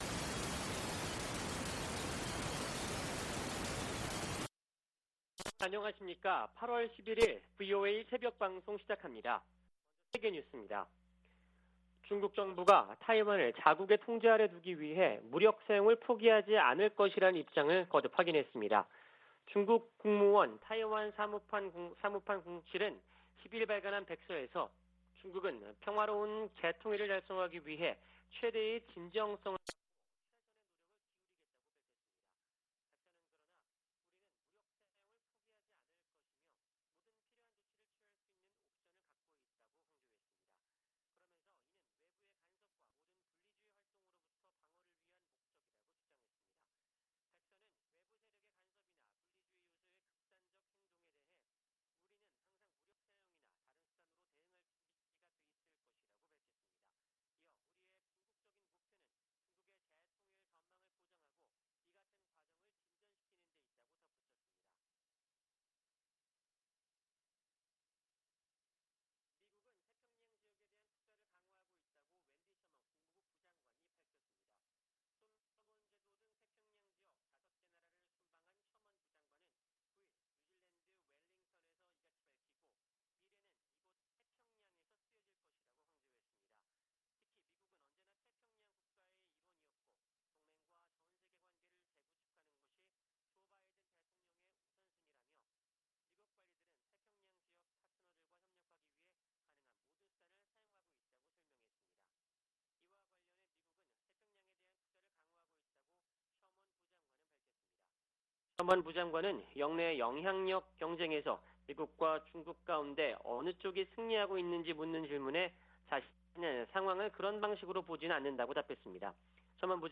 VOA 한국어 '출발 뉴스 쇼', 2022년 8월 11일 방송입니다. 박진 한국 외교부 장관이 왕이 중국 외교부장과의 회담에서 사드 3불은 중국과의 합의나 약속이 아니라는 점을 분명히 했다고 밝혔습니다. 북한이 올해 다수의 가상화폐 탈취 사건에 관여한 것으로 지목되는 가운데 피해와 위협이 증가할 것이라는 분석이 나왔습니다. 서울 유엔인권사무소가 북한군에 피살된 한국 해양수산부 공무원 사건 진상규명을 강조했습니다.